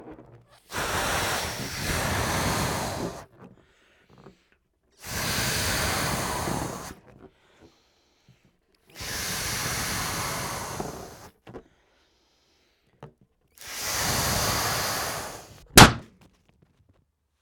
balloon_blow_pop_ST
balloon blow burst h4n inflating pop zoom sound effect free sound royalty free Sound Effects